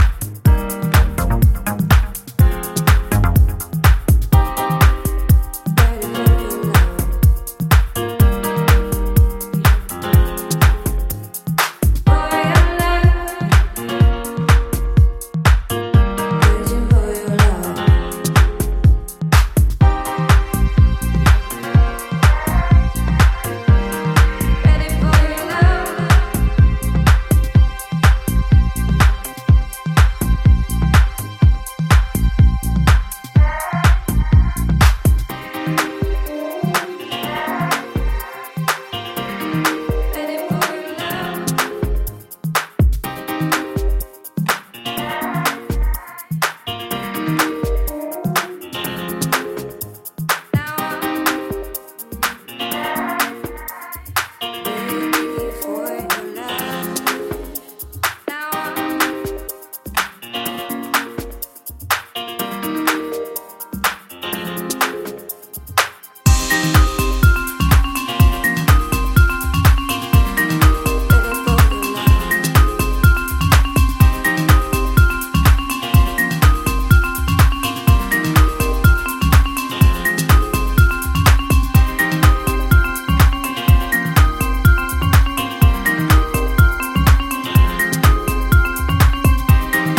ヴァイブのソロが入ったメランコリックなディープ・ハウスのオリジナル